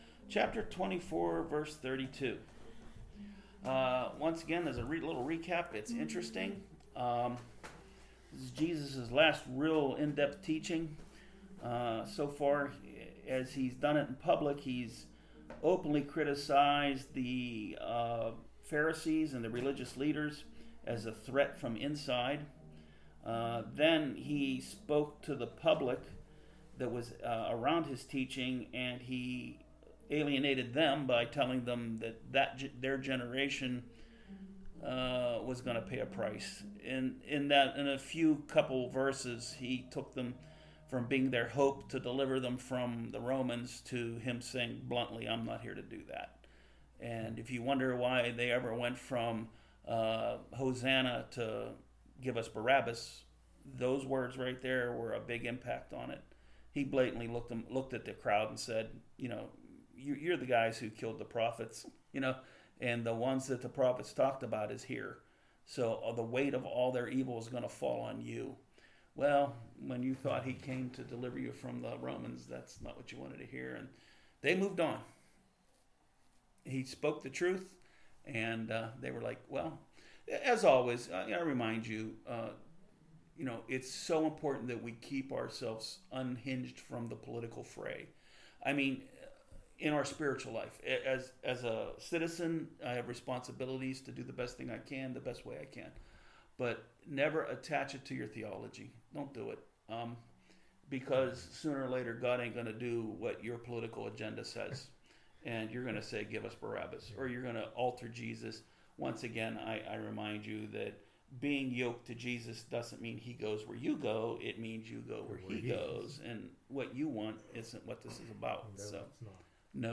Bible Study 2024-05-23